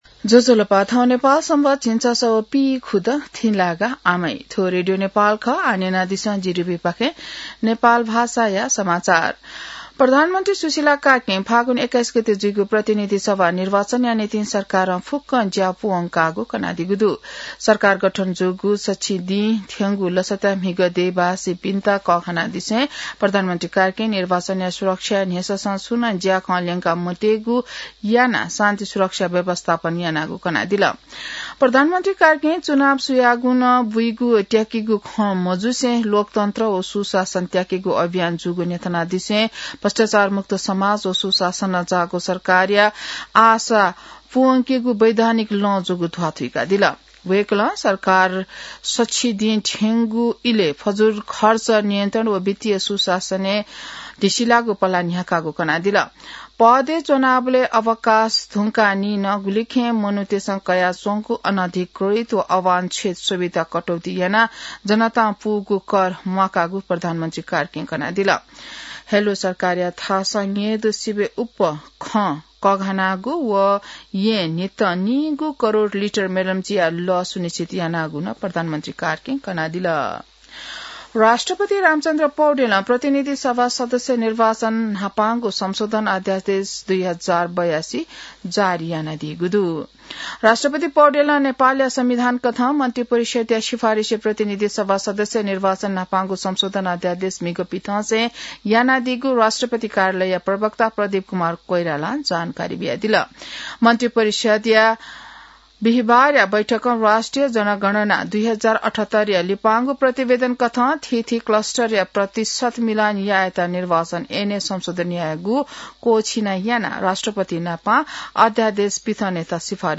नेपाल भाषामा समाचार : ५ पुष , २०८२